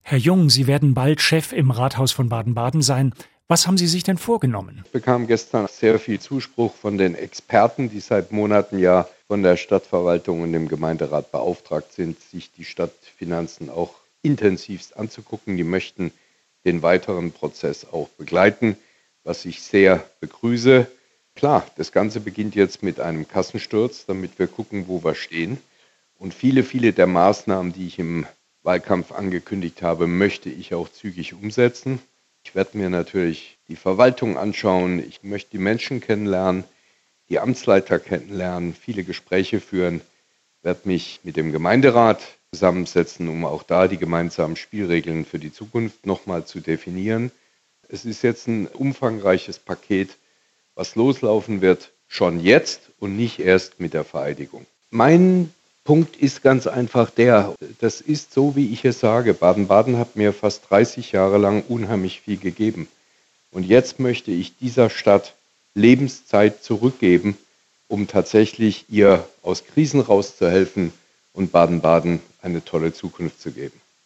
Thomas Jung im SWR-Interview